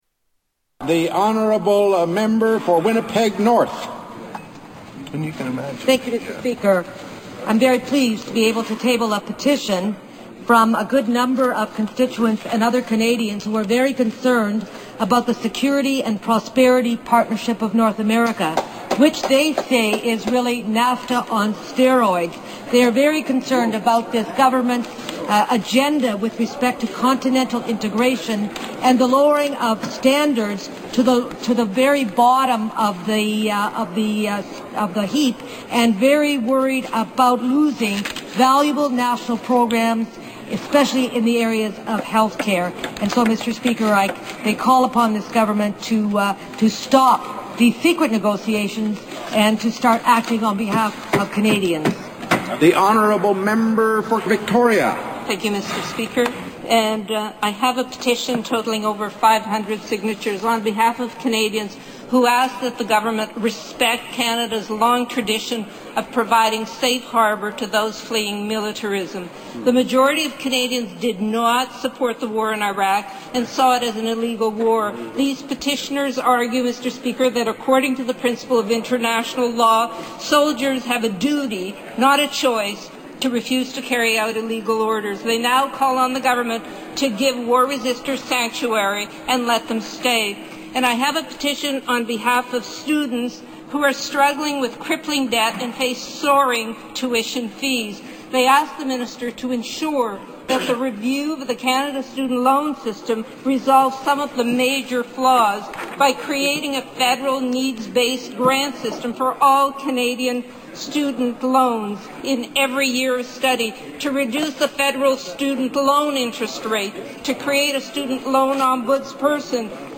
Tags: Historical Top 10 Censored News Stories 2009 Censored News Media News Report